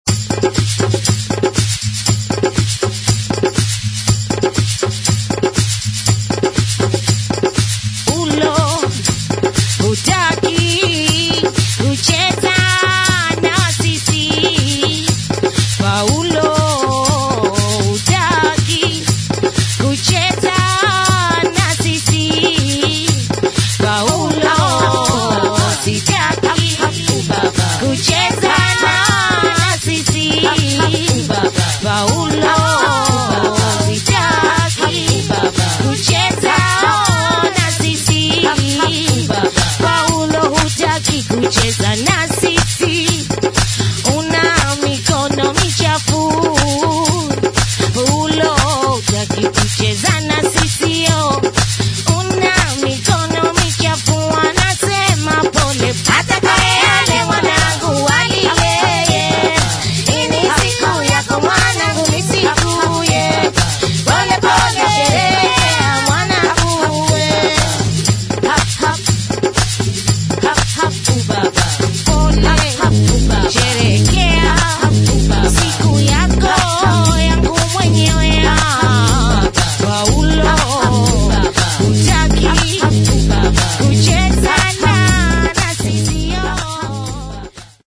[ HOUSE / AFRO / SOUL ]